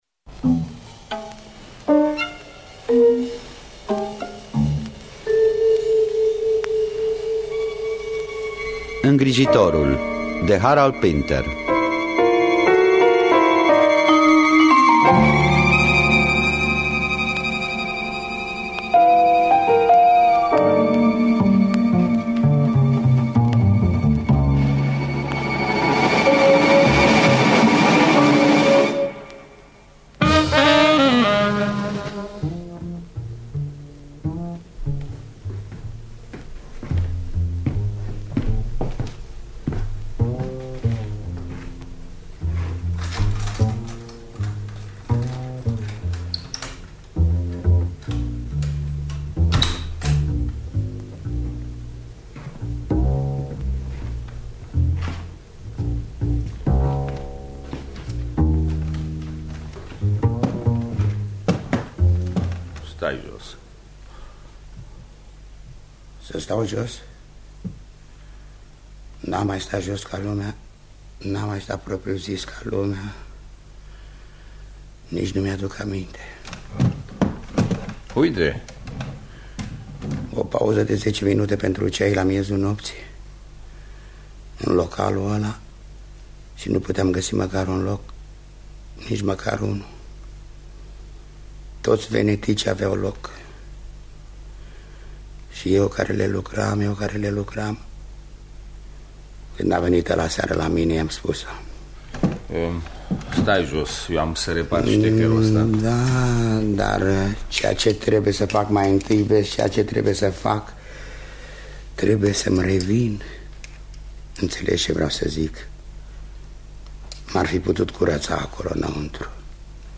În distribuție: Vasile Nițulescu, Ion Caramitru, Dan Condurache.